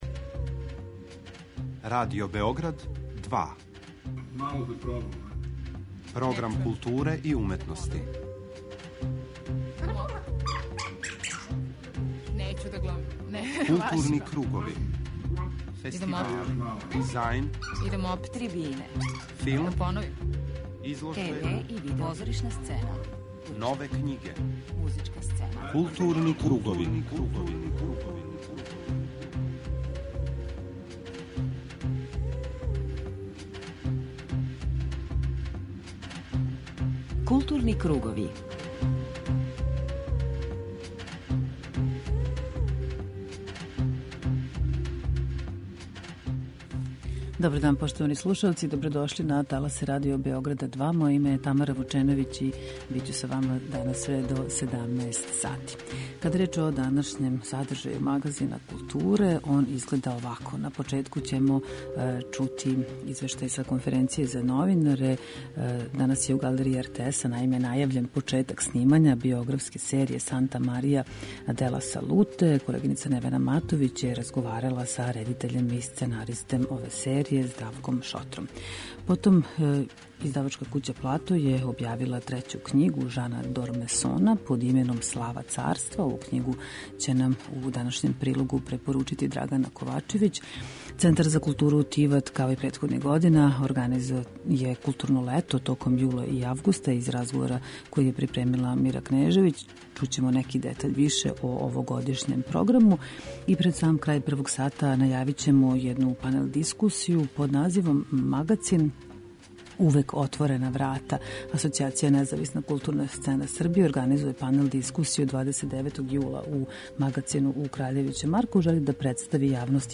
У првом делу емисије упознаћемо вас са културним актуелностима, а у тематском делу посвећеном визуелним уметностима, данас гостују два млада уметника.